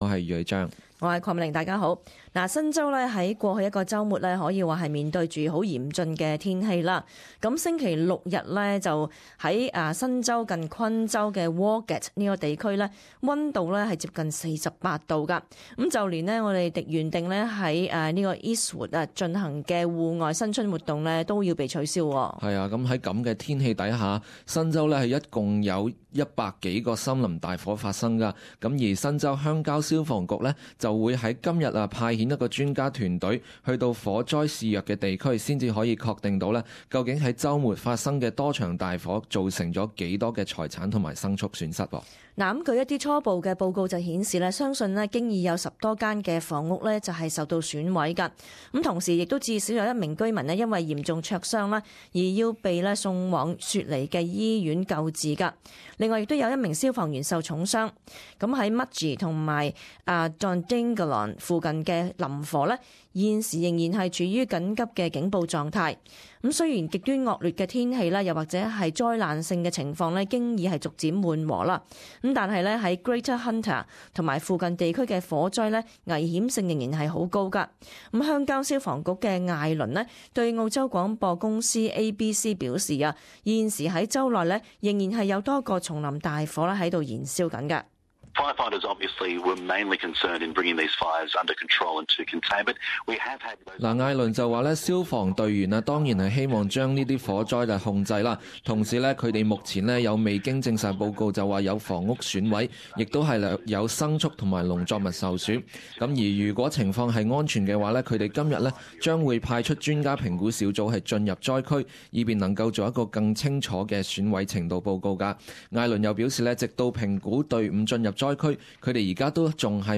【時事報導】 新州山林大火損失尚待評估